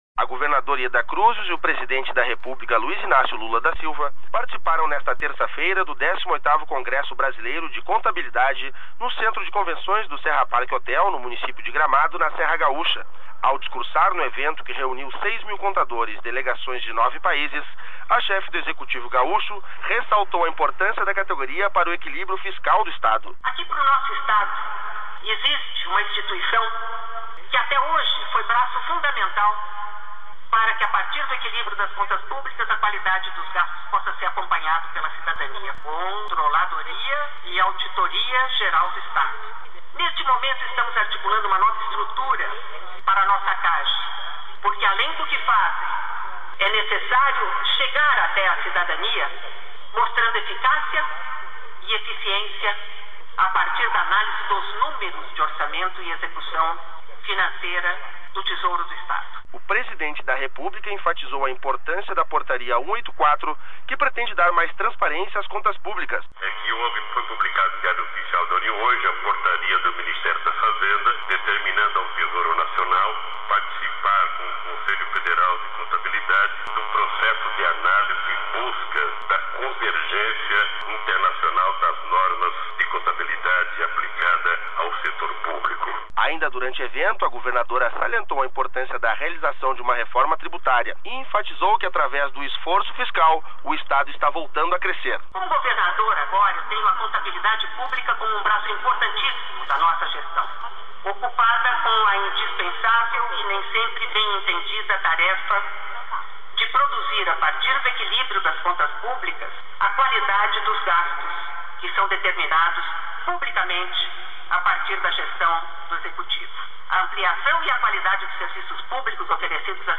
Yeda reitera compromisso com ajuste fiscal durante congresso em Gramado
Juntamente com o presidente Luiz Inácio Lula da Silva a Governadora participou, nesta terça-feira (26), do 18º Congresso Brasileiro de Contabilidade realizado no Centro de Convenções do Serra Park Hotel em Gramado.